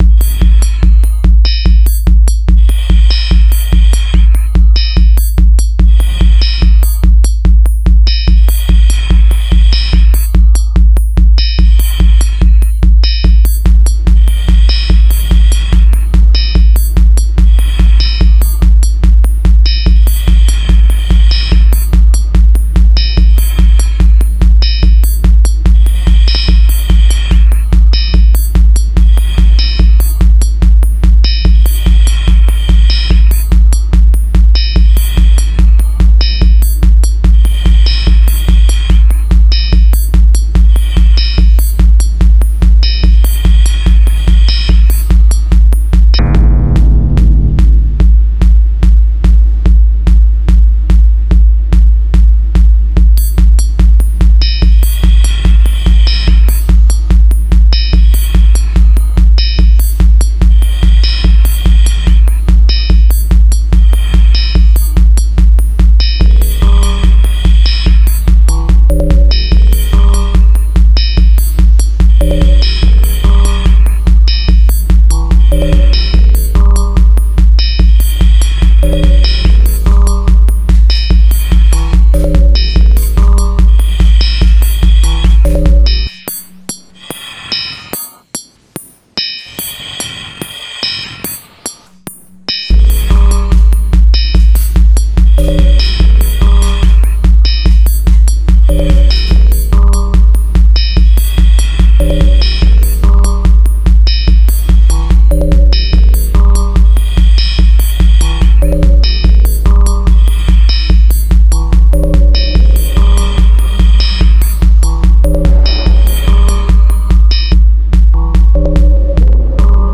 This is pretty much 3 instances of Opal and some sample snippets. It’s a bit discordant and not what I usually make at all, but interesting that it inspired a different vibe.